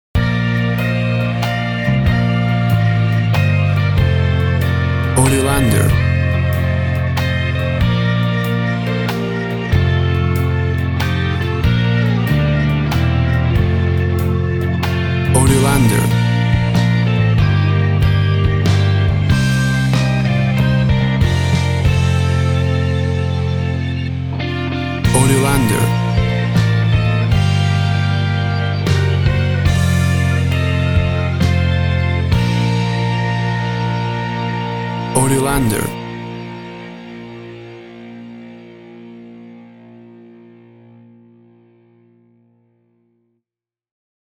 Tempo (BPM) 100